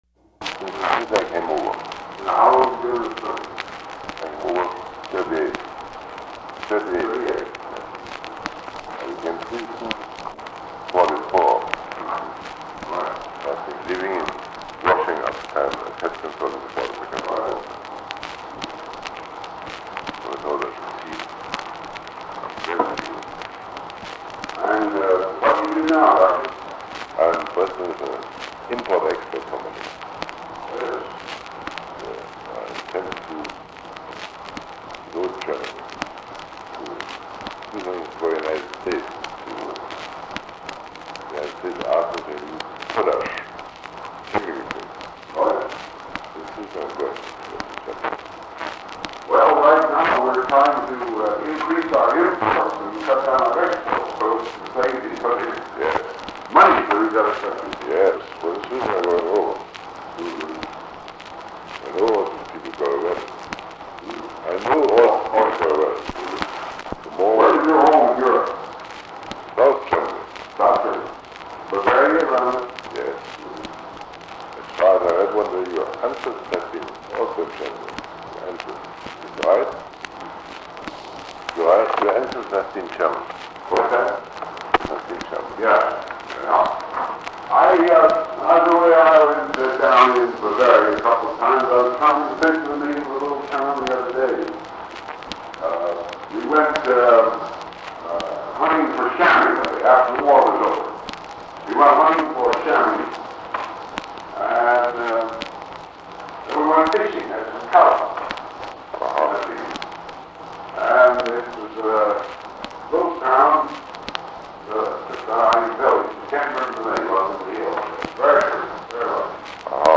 Secret White House Tapes | Dwight D. Eisenhower Presidency